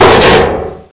klonk7.mp3